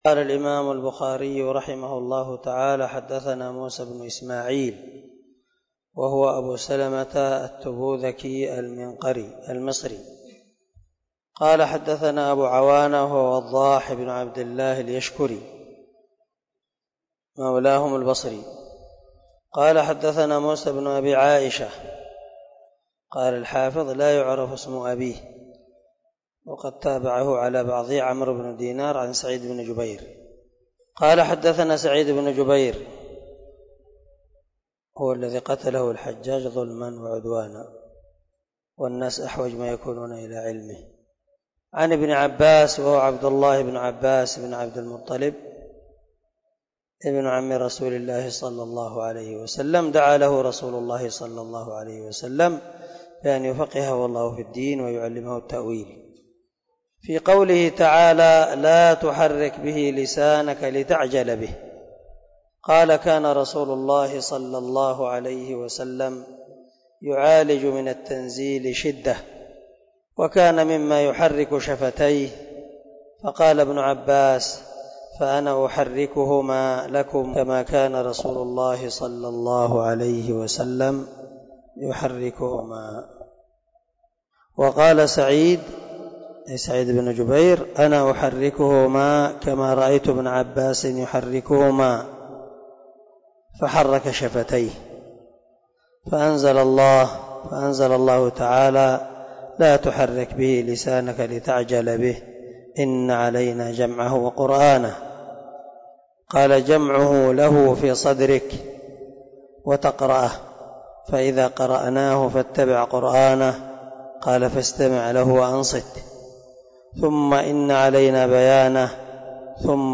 007الدرس 5 من كتاب بدء الوحي حديث رقم ( 5 ) من صحيح البخاري